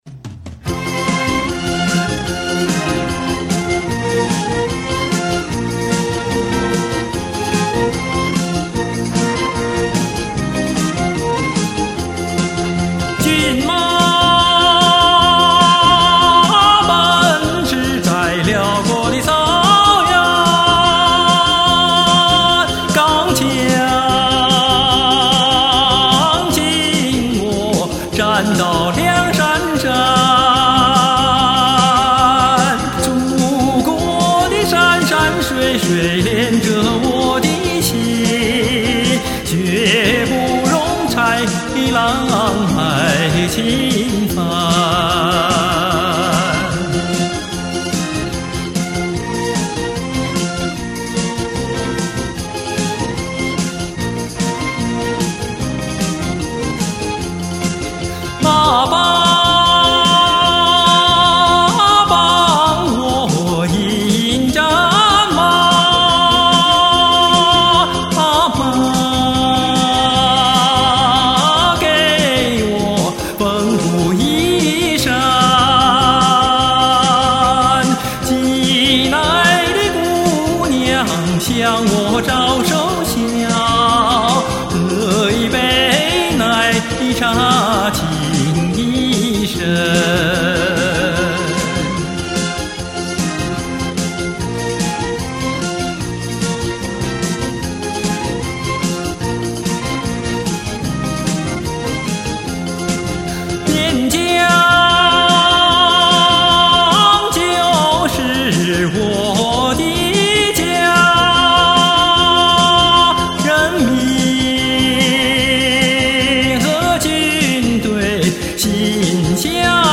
感冒好了，嗓子也基本恢复了，高兴了来唱一首老歌，给大家助助兴！